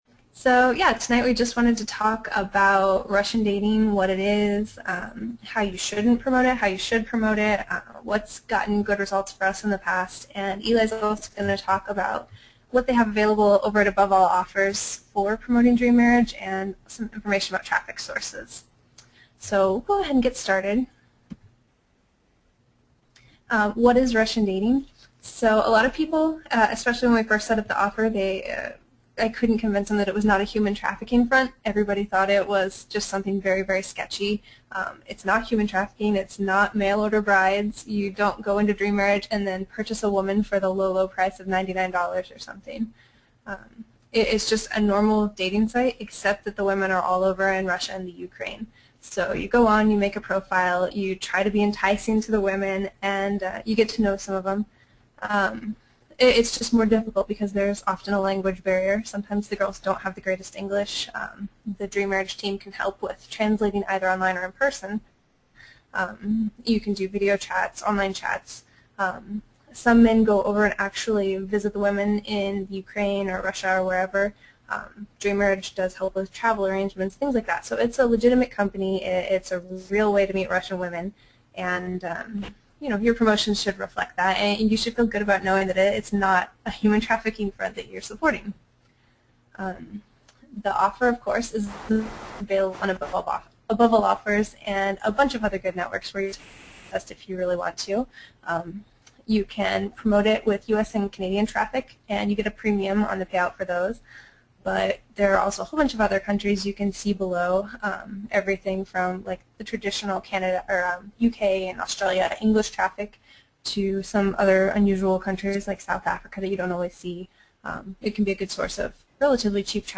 Webinar Replay – Hot Russian Women!